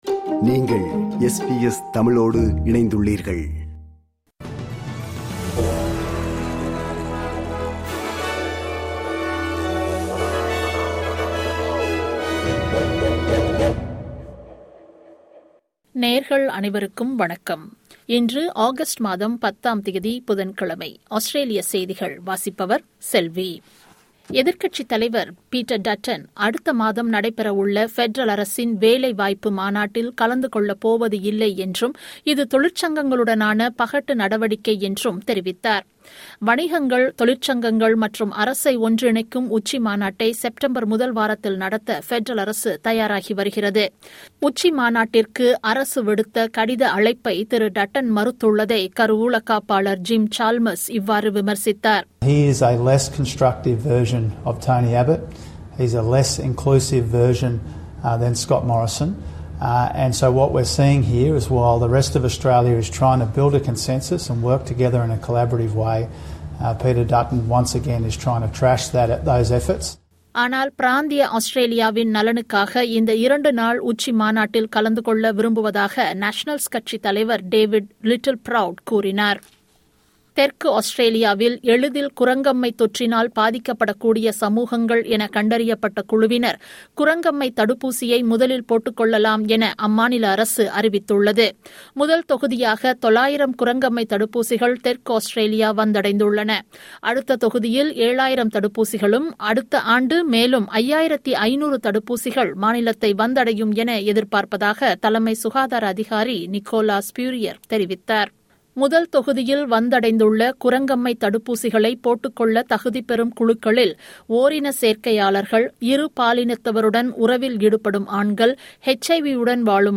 Australian news bulletin for Wednesday 10 Aug 2022.